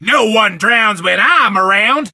buzz_start_vo_01.ogg